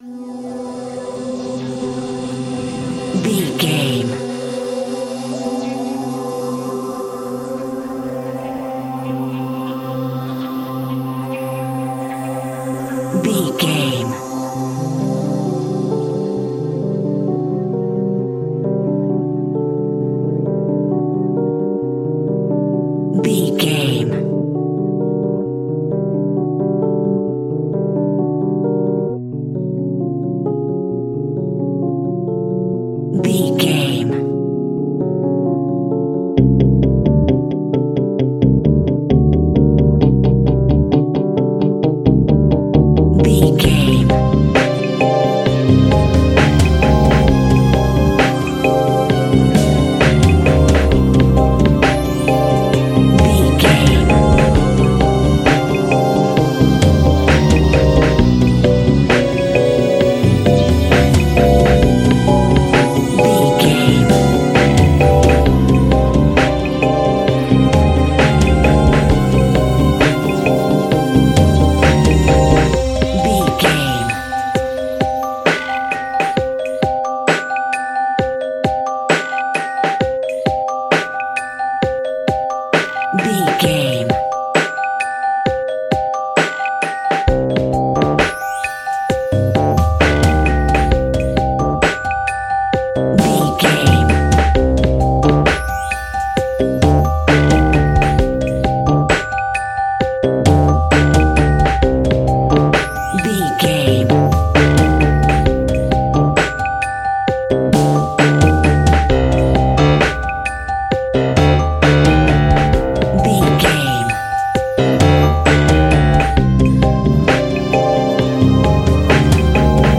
A Haunting Music Theme.
In-crescendo
Aeolian/Minor
ominous
eerie
synthesiser
piano
electric piano
drums
strings
Horror Ambience
Horror Synths
Scary Strings